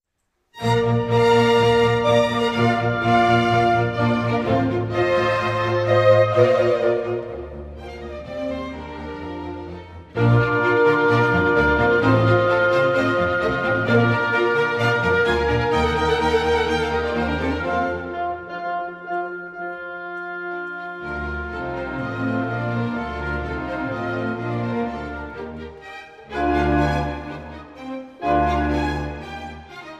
plays with a rich warmth and dark, richly burnished tone
Allegro 7.23